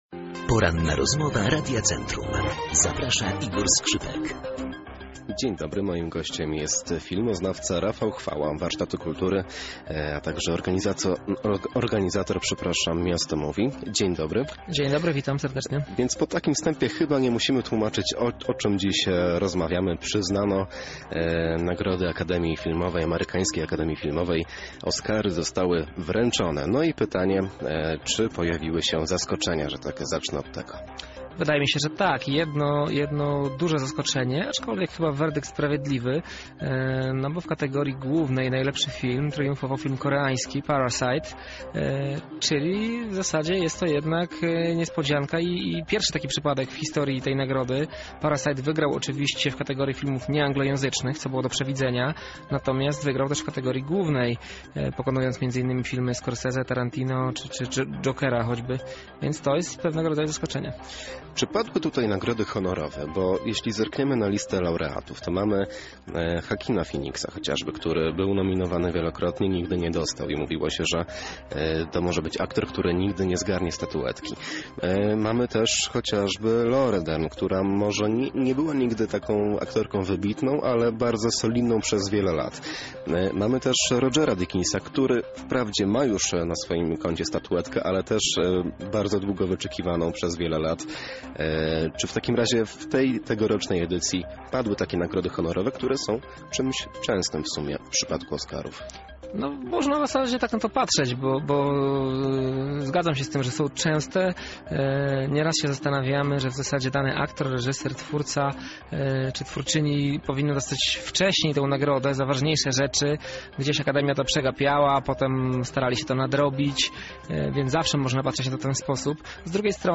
Poniżej pełna rozmowa oraz lista laureatów: